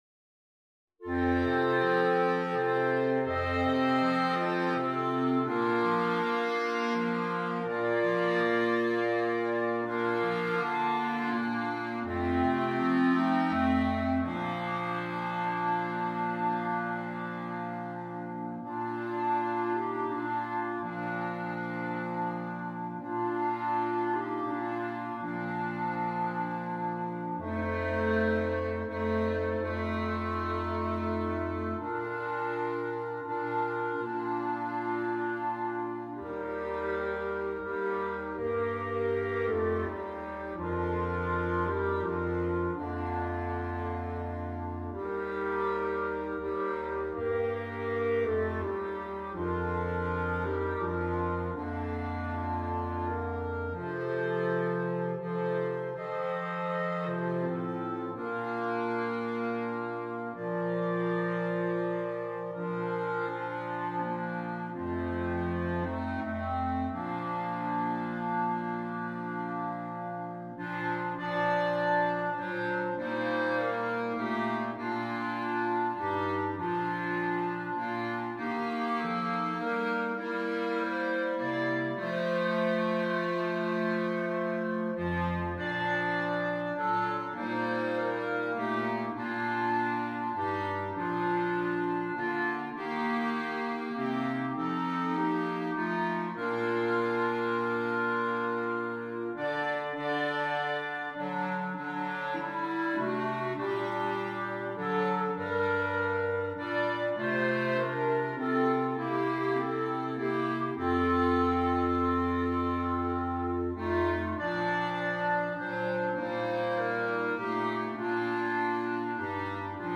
a montage of favorite Christmas carols